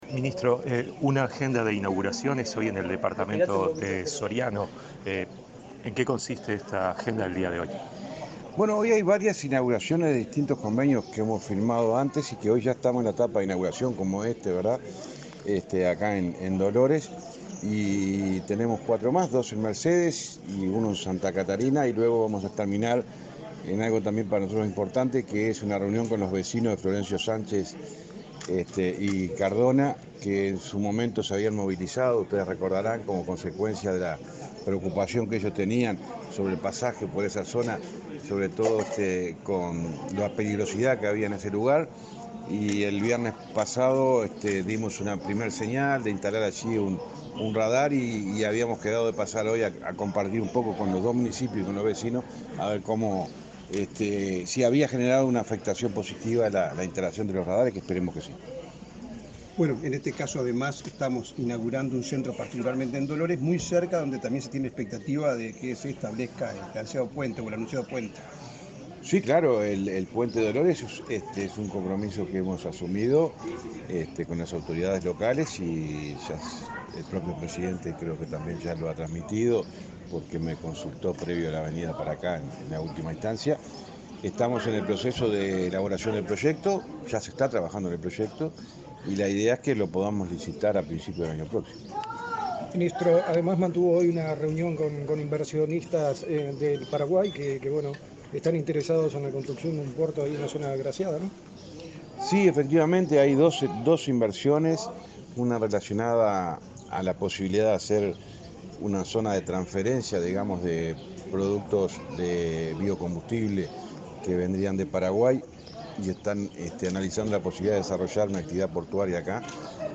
Declaraciones a la prensa del ministro de Transporte, José Luis Falero